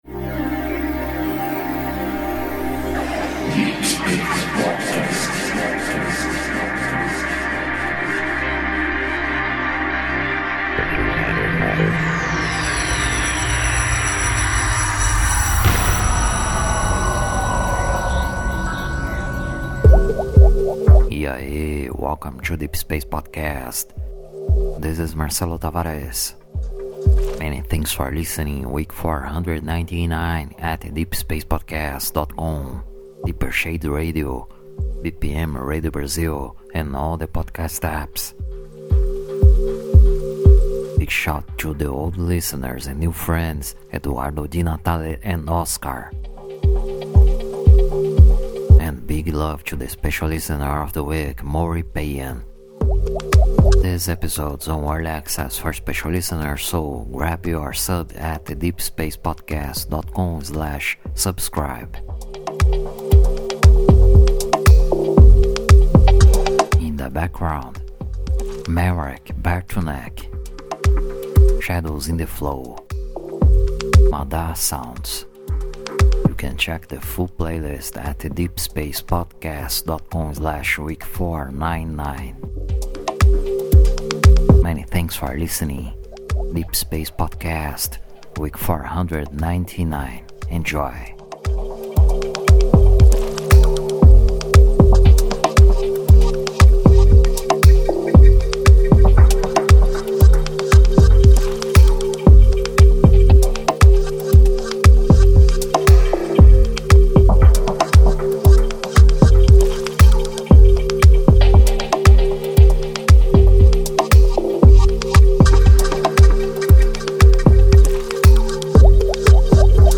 exclusive guestmix in the 2nd hour
special deep mix